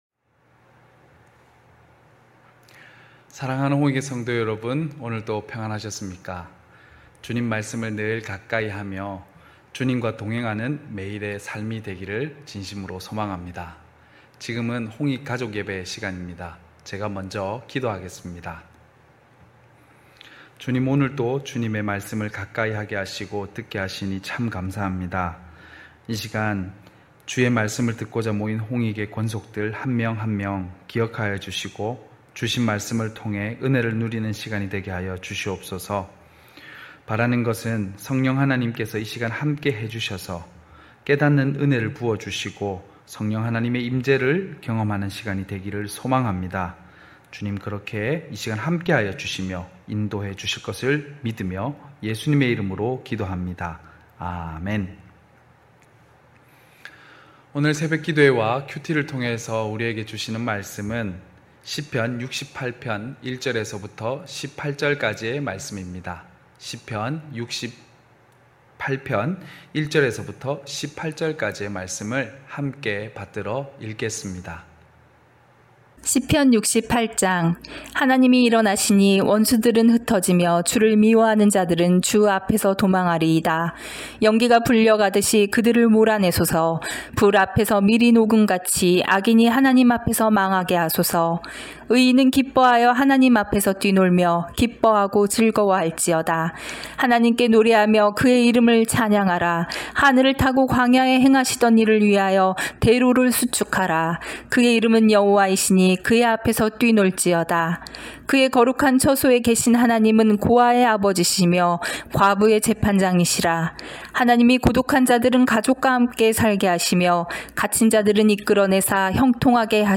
9시홍익가족예배(8월24일).mp3